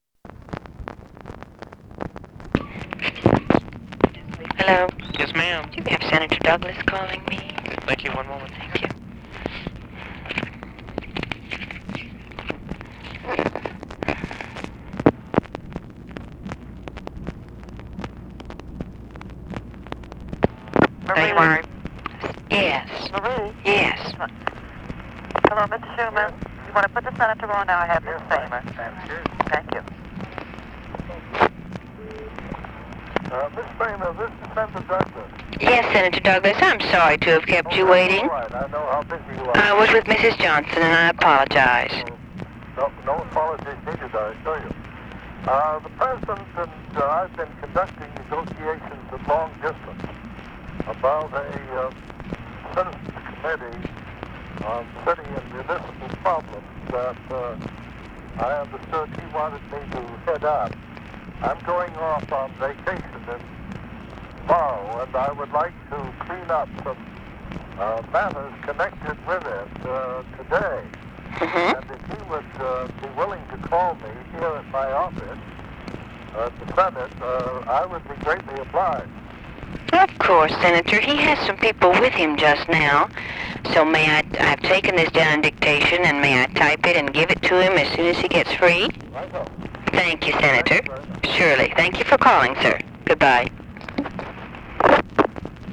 Conversation with PAUL DOUGLAS
Secret White House Tapes | Lyndon B. Johnson Presidency